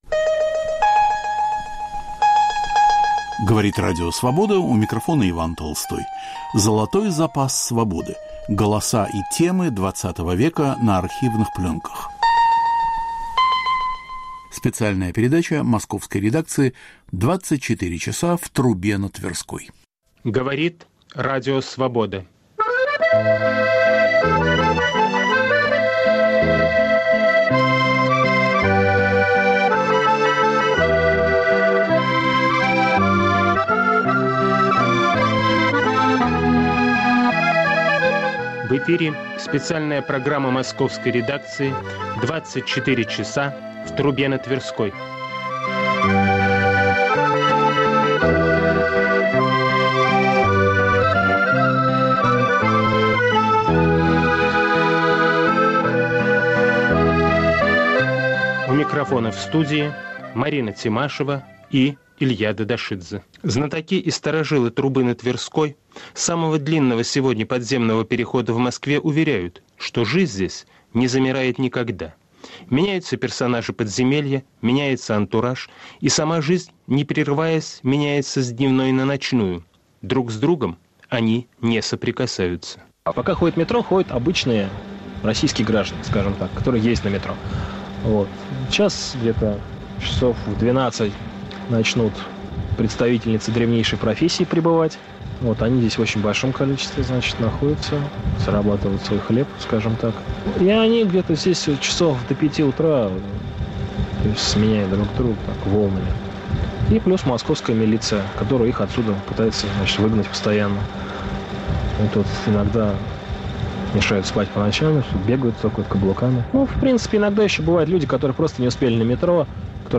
Специальная передача Московской редакции. Жизнь в самом длинном подземном переходе в Москве. Рассказывают его знатоки и старожилы.